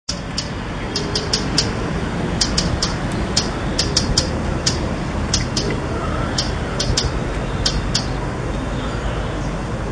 Chercán, ratonera común (Arg, Bol), masakaragau’i (Par), cambaxirra (Bra)
Nombre científico:Troglodytes aedon chilensis